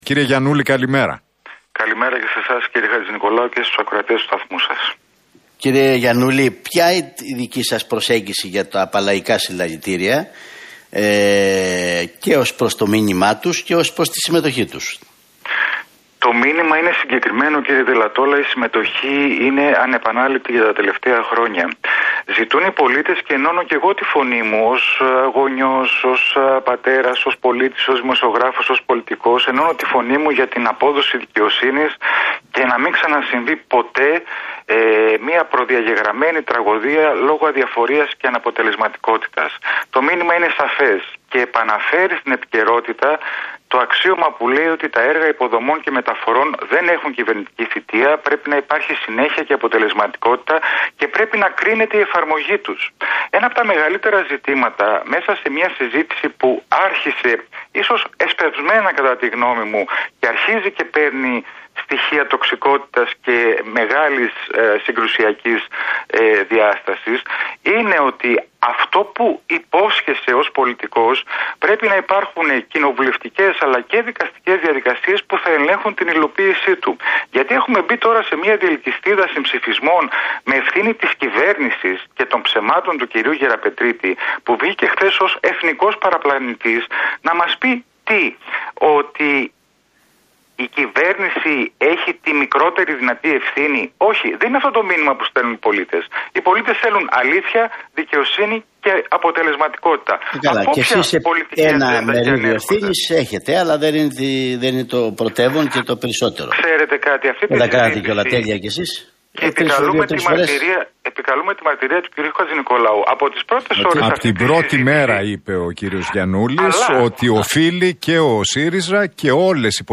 Γιαννούλης στον Realfm 97,8: Τα έργα υποδομών και μεταφορών δεν έχουν κυβερνητική θητεία